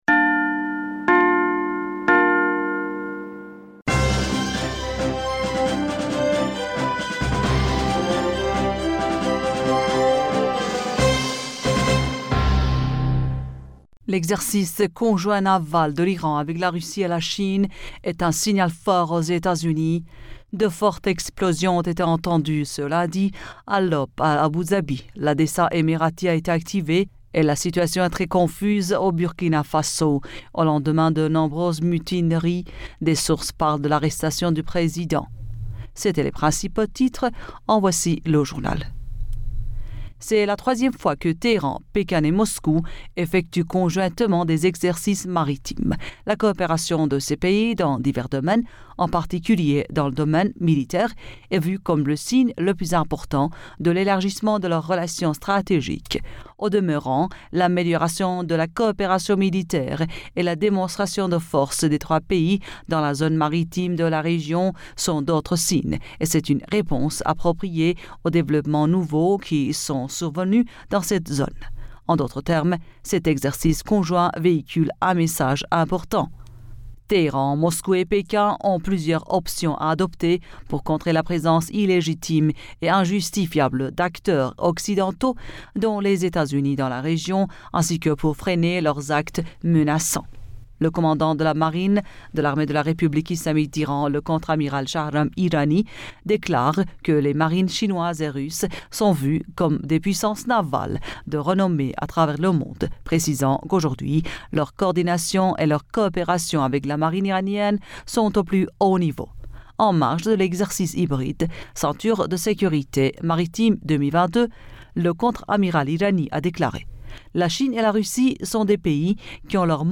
Bulletin d'information Du 24 Janvier 2022